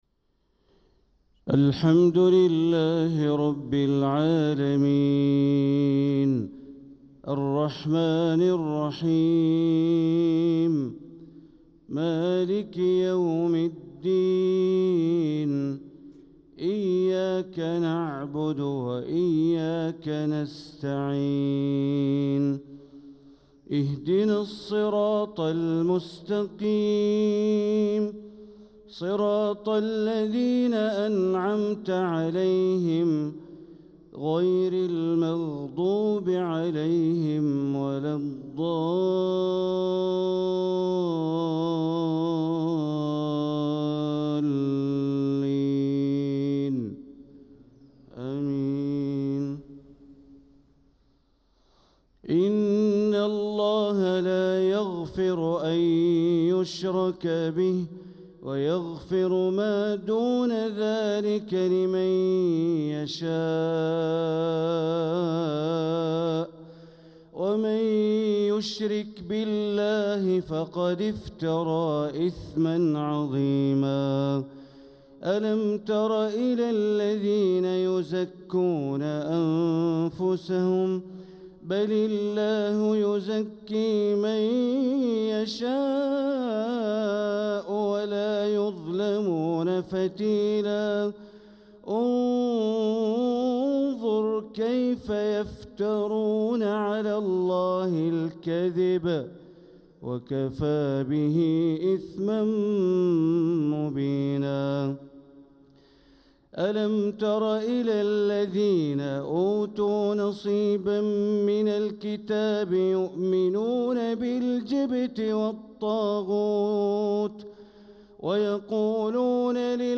صلاة الفجر للقارئ بندر بليلة 17 ربيع الآخر 1446 هـ